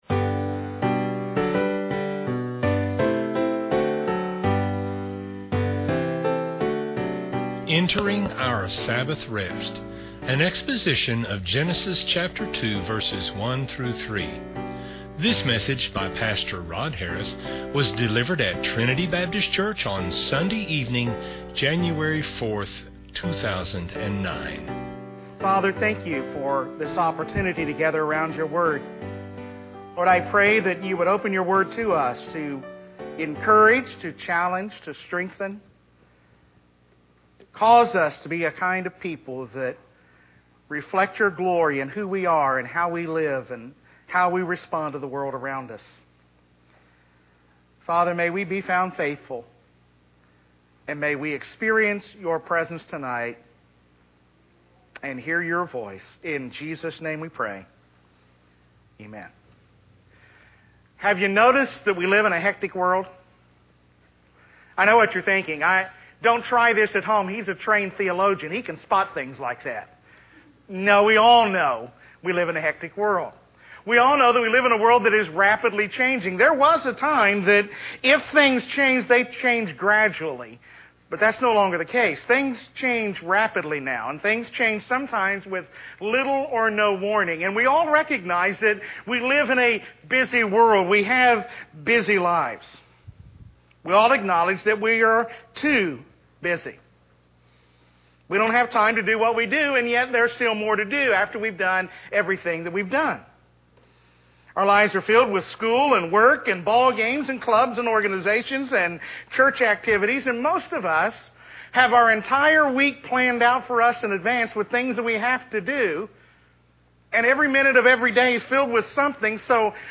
An exposition of Genesis 2:1-3.